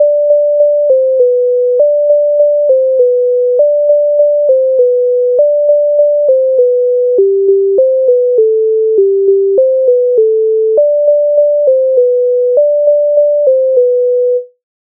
MIDI файл завантажено в тональності G-dur
го го го коза Українська народна пісня з обробок Леонтовича с.37
Ukrainska_narodna_pisnia_ho_ho_ho_koza.mp3